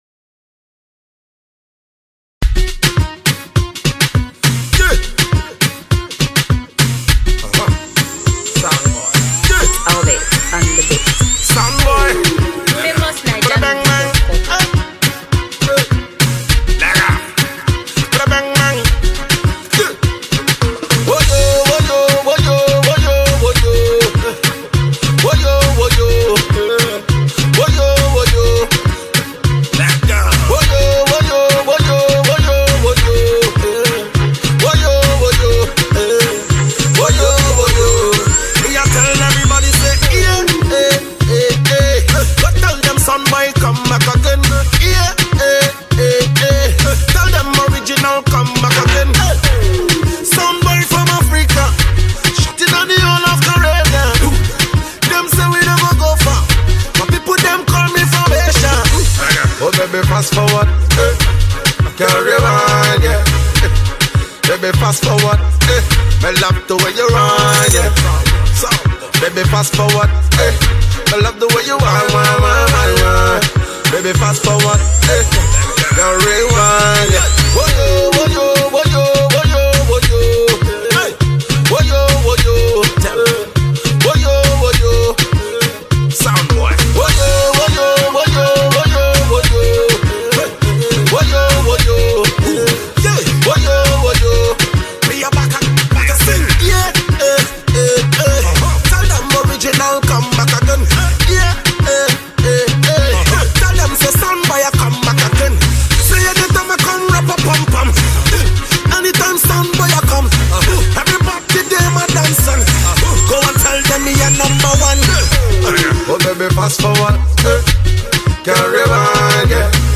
An incredible beat